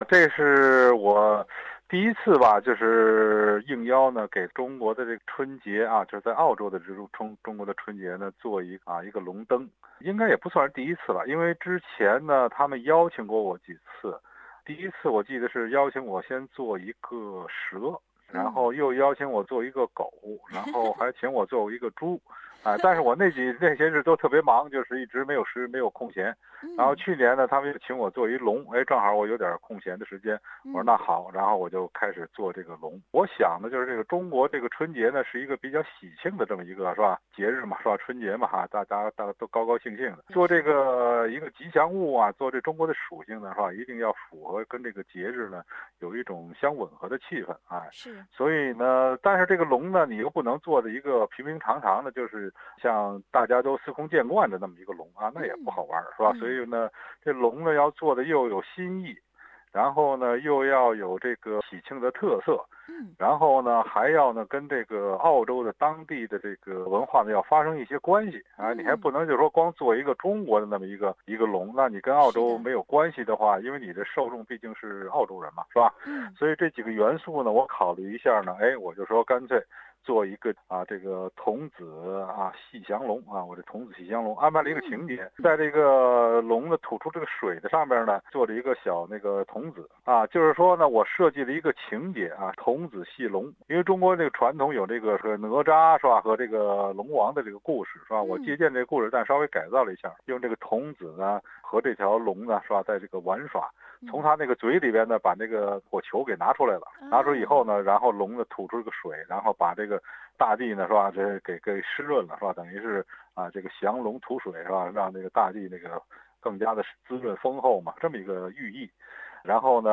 专访童子戏龙彩灯设计者，著名现当代艺术家——关伟